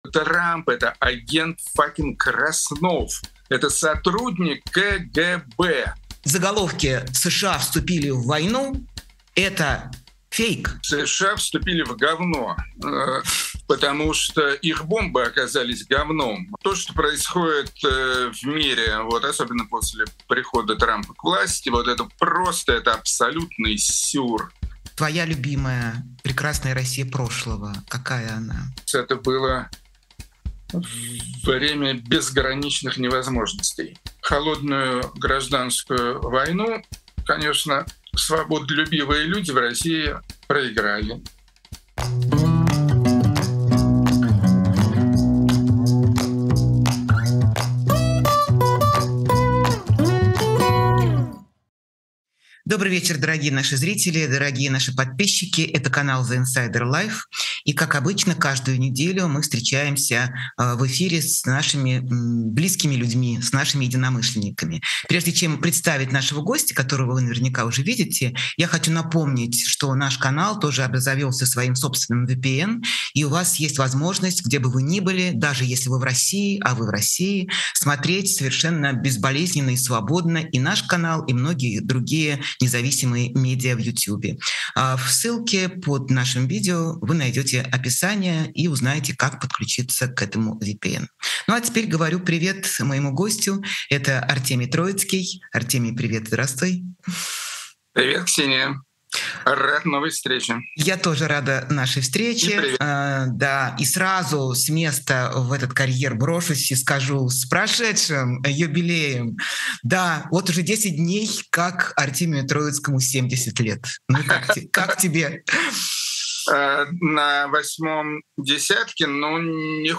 Эфир ведёт Ксения Ларина
Гость — музыкальный критик Артемий Троицкий.